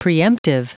Prononciation audio / Fichier audio de PREEMPTIVE en anglais
Prononciation du mot preemptive en anglais (fichier audio)